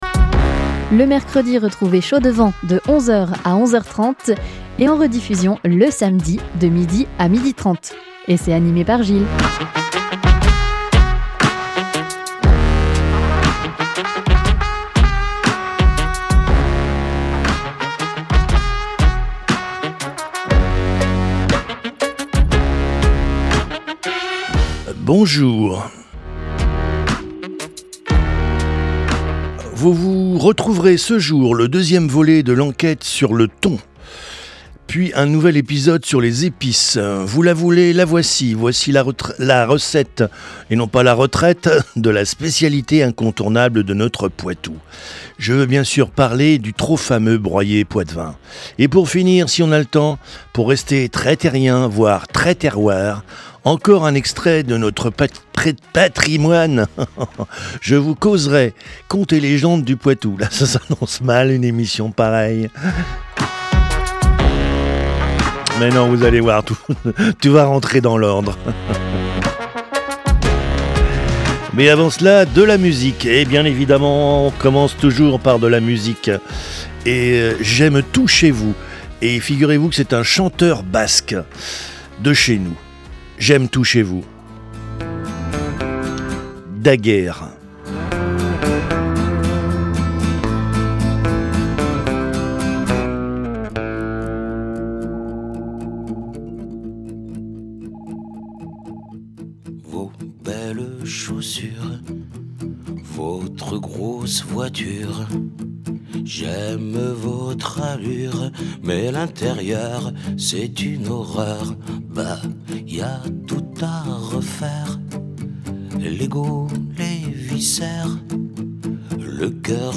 avec anecdotes, témoignages , rubriques , recettes , conseils de chef et musiques !